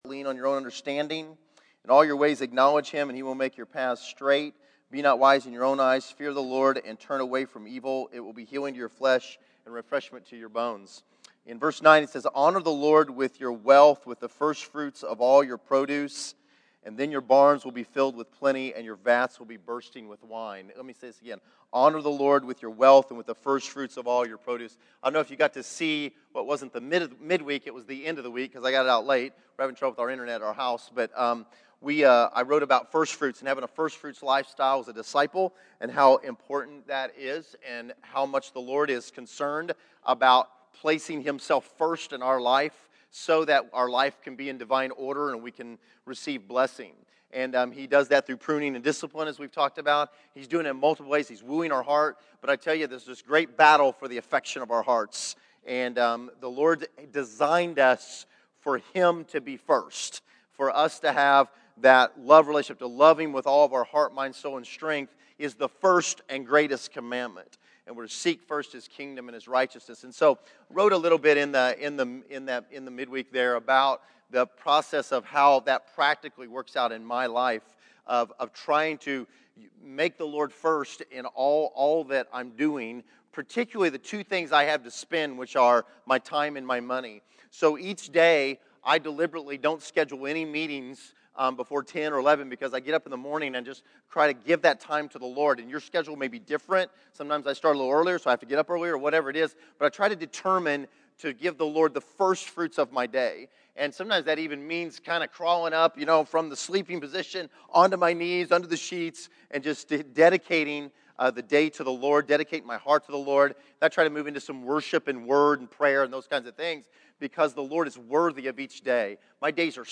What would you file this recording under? Category: Scripture Teachings